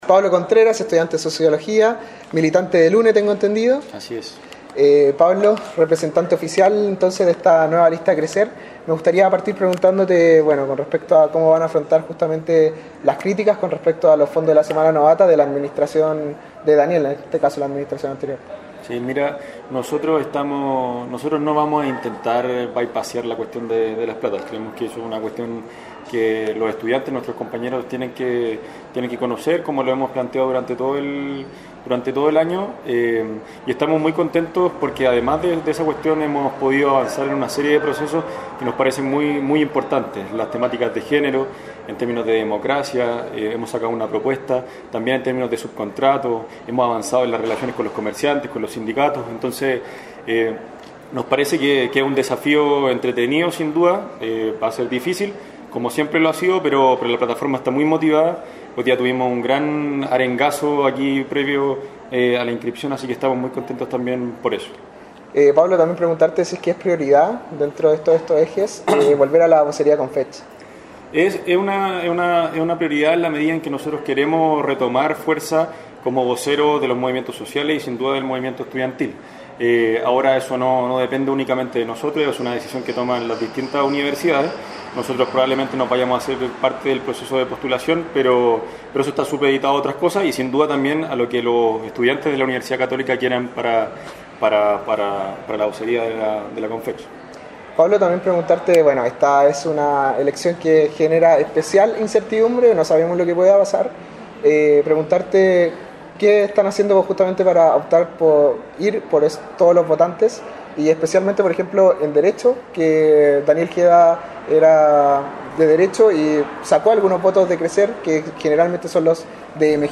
En la entrevista señaló que no van a eludir el tema de los dineros de la Semana Novata.